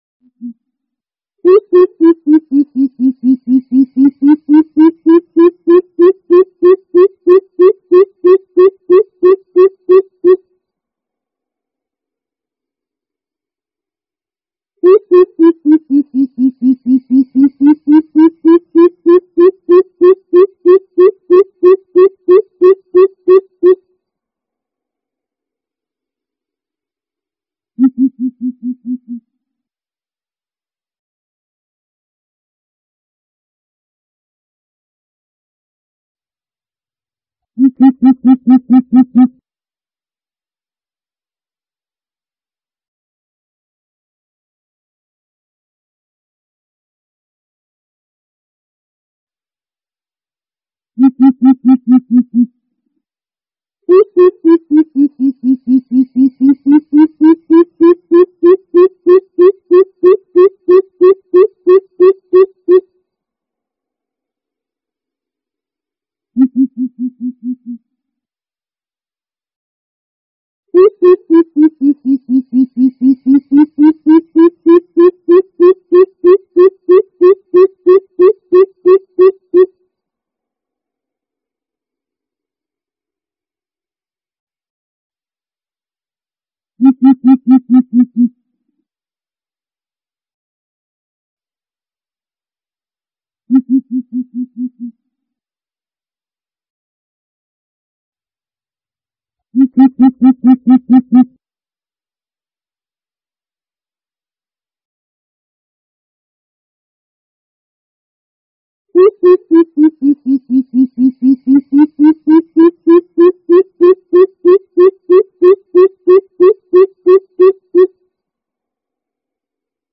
Tiếng chim Bìm Bịp rừng kêu
Tải tiếng chim bìm bịp rừng kêu mp3 hay nhất, chuẩn nhất không có tạp âm. Tiếng chim bìm bịp rừng kêu là âm thanh quen thuộc trong các khu vực núi rừng hoang dã.
Âm thanh tiếng chim Bìm Bịp rừng mang một sắc thái trầm ấm, sâu lắng và có độ vang đặc trưng của núi rừng hoang dã. Tiếng kêu "bịp bịp" đều đặn không chỉ gợi lên không gian yên bình của vùng quê hay những cánh rừng rậm rạp mà còn là tư liệu âm thanh cực kỳ đắt giá.